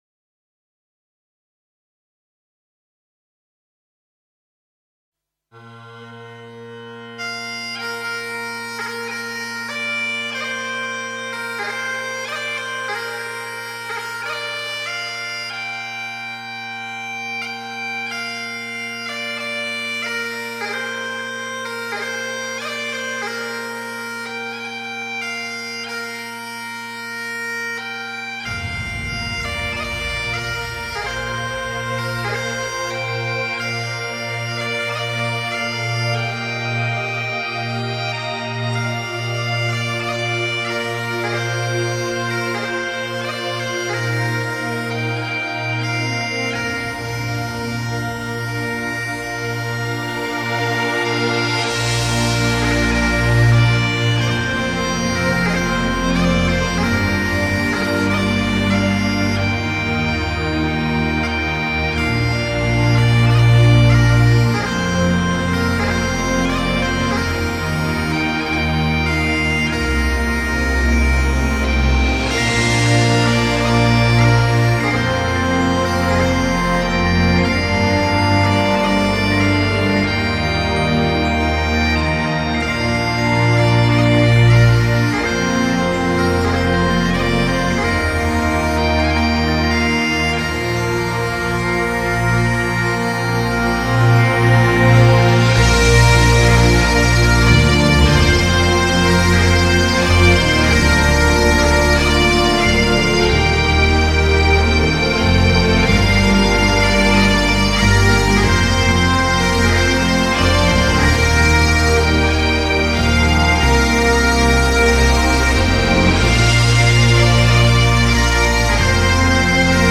Here’s something a bit more traditional, and if your brain done got all swolled  up from last night’s festivities, maybe just an .mp3 will do: